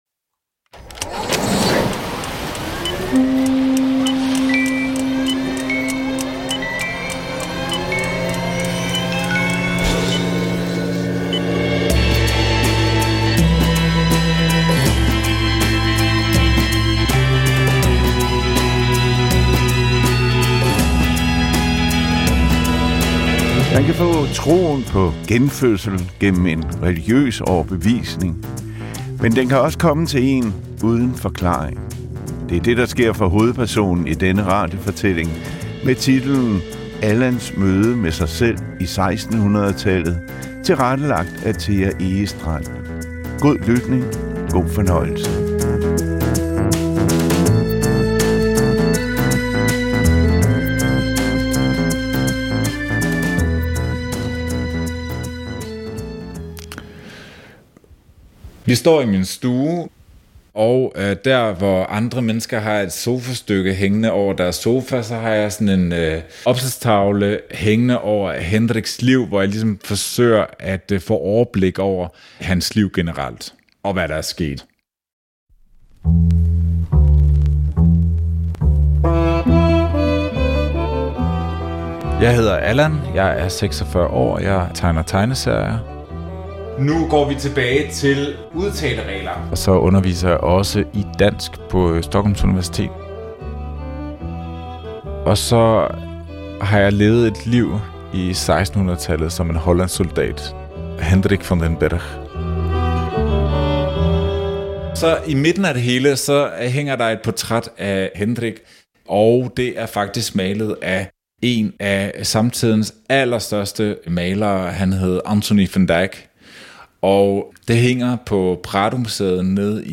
… continue reading 123 episodes # Samfund # Radiodrama # Historiefortælling # Dokumentarfilm